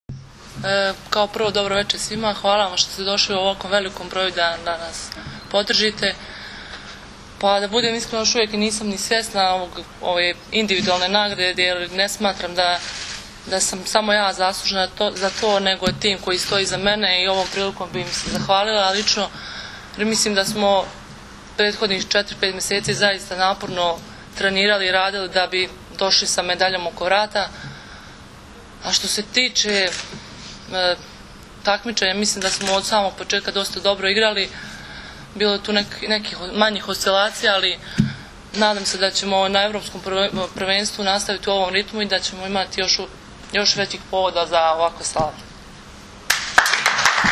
U salonu „Beograd“ aerodroma „Nikola Tesla“ održana je konferencija za novinare, na kojoj su se predstavnicima medija obratili Maja Ognjenović, Brankica Mihajlović, Milena Rašić, Jovana Brakočević i Zoran Terzić.
IZJAVA BRANKICE MIHAJLOVIĆ